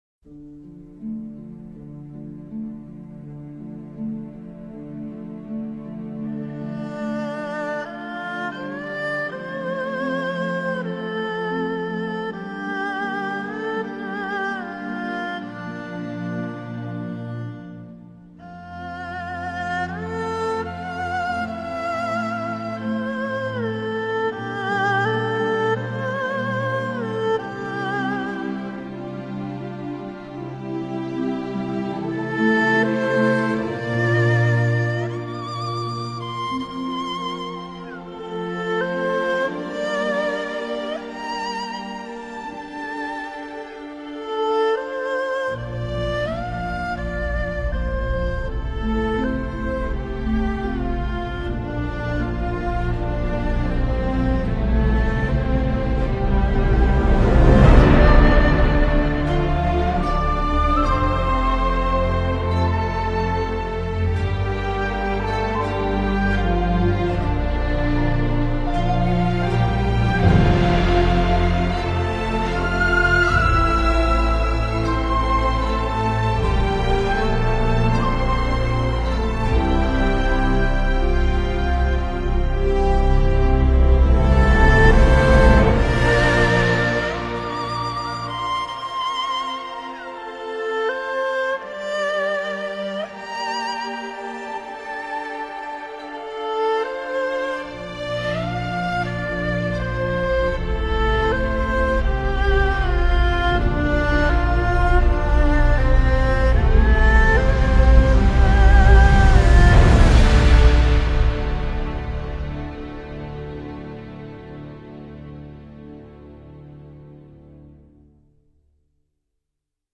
respirar.mp3